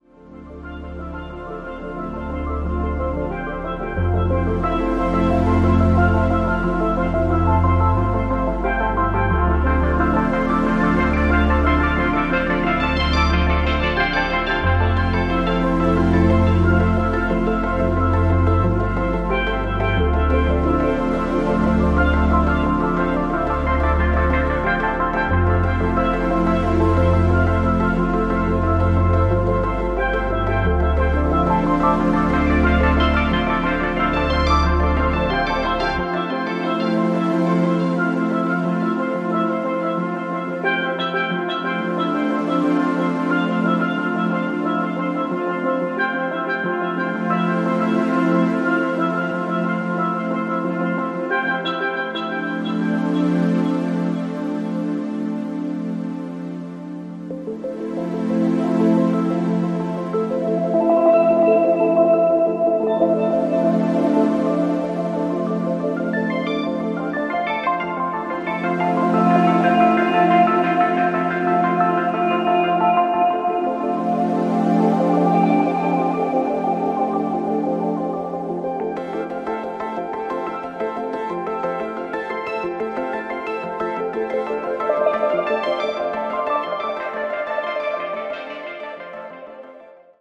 今回、さらにリマスタリングを施したことで音質がさらに良くなっているそうですので(※ご本人談)、そのあたりもお楽しみに！